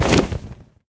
Minecraft Version Minecraft Version snapshot Latest Release | Latest Snapshot snapshot / assets / minecraft / sounds / mob / enderdragon / wings3.ogg Compare With Compare With Latest Release | Latest Snapshot
wings3.ogg